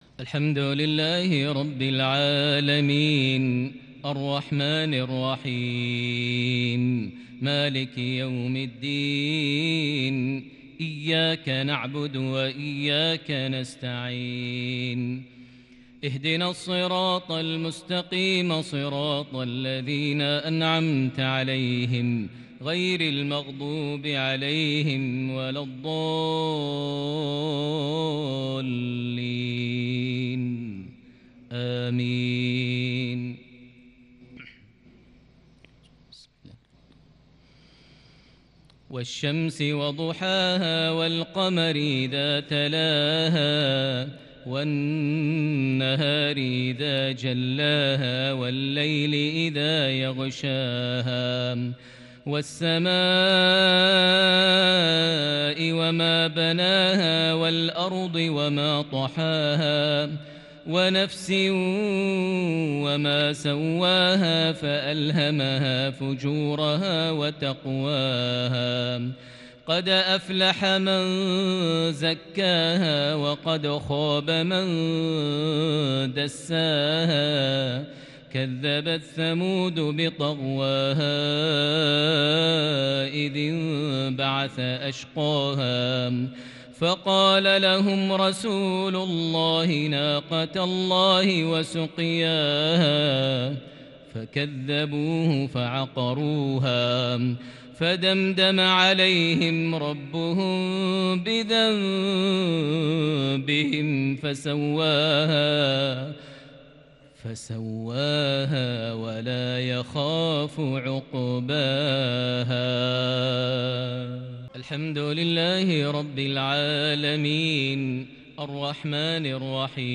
تلاوة بلمحات كردية لسورتي الشمس - الليل | مغرب 30 صفر 1442هـ > 1442 هـ > الفروض - تلاوات ماهر المعيقلي